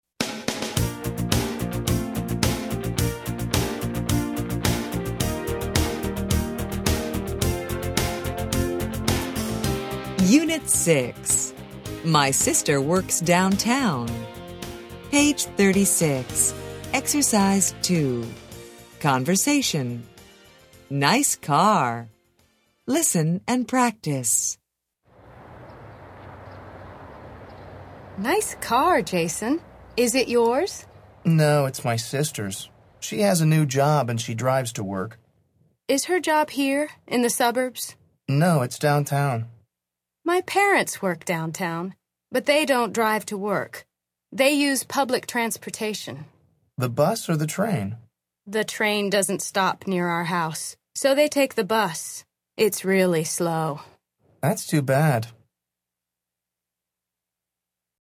Interchange Third Edition Intro Unit 6 Ex 2 Conversation Track 16 Students Book Student Arcade Self Study Audio